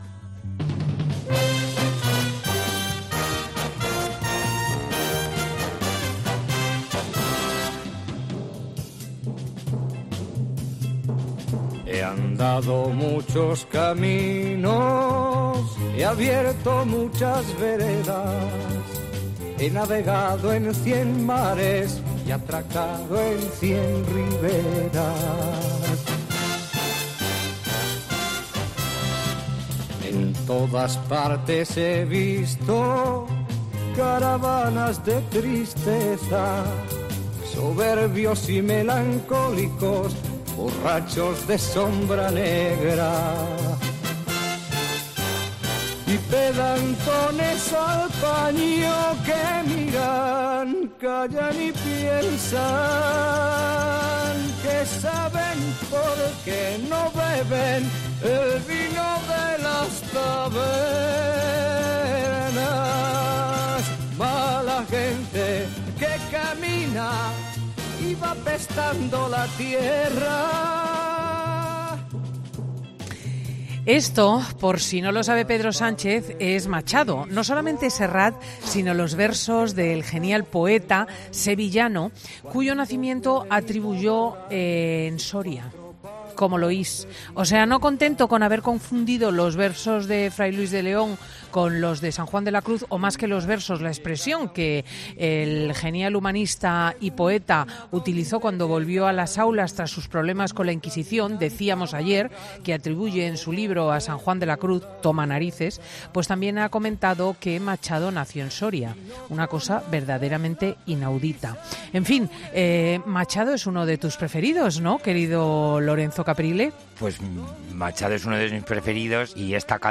Tampoco a Lorenzo Caprile, que ha compartido los micrófonos con Cristina L. Schlichting en Fin de Semana para hacer un repaso de la vida de este icono de la moda internacional.